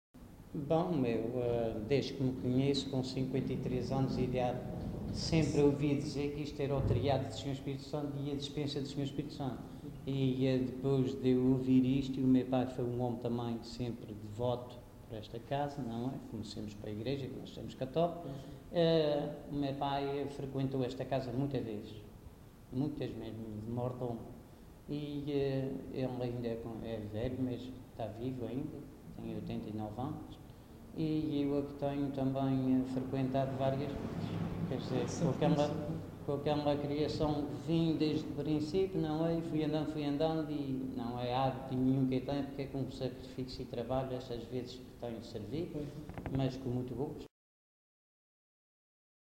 LocalidadeFontinhas (Praia da Vitória, Angra do Heroísmo)